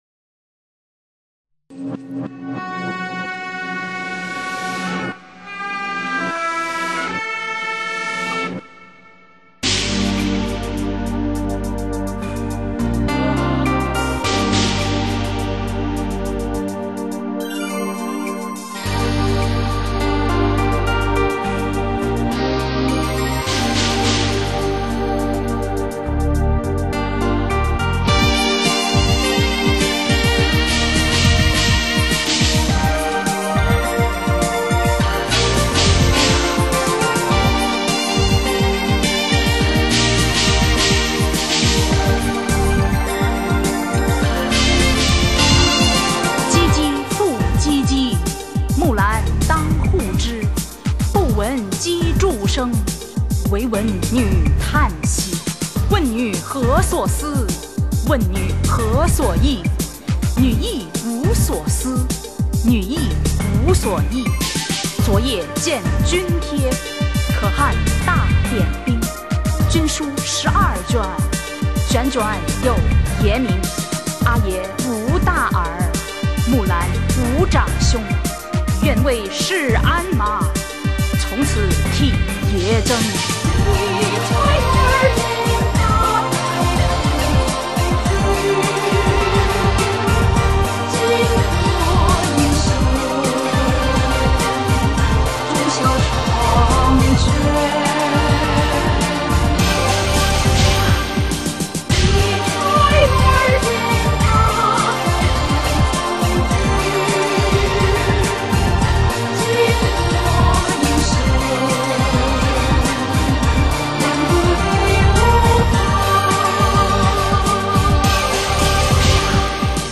全曲以朗诵形式，配以 (RAP BEAT)及锣等效果，作一个 革命性的突破，副歌的点题，正 是此曲的画龙点睛。